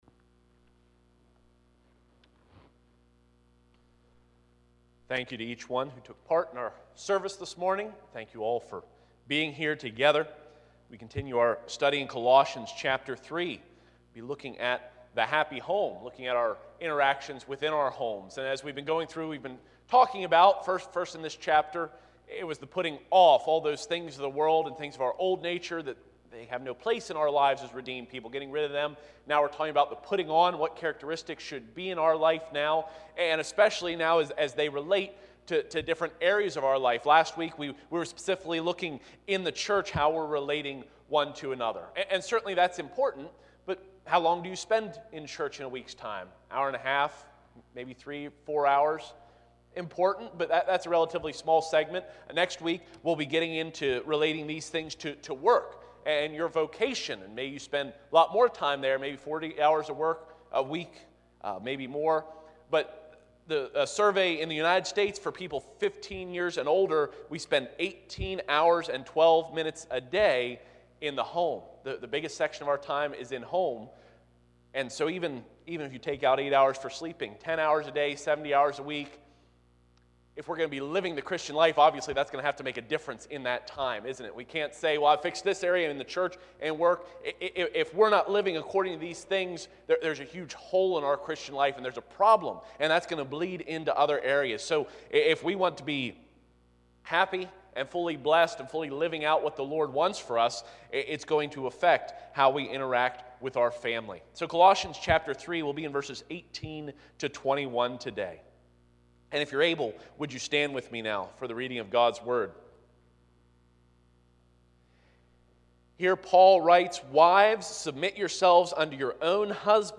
Colossians 3:18-21 Service Type: Sunday 9:30AM I. The Lady Submits v. 18 II.